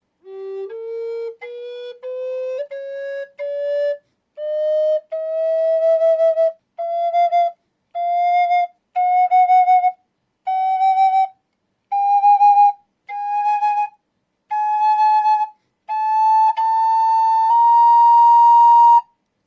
~ All available in 6 hole Flute & 5 hole Flute ~
~ Canyon Spirit Flute ~
Listen to  18 notes scale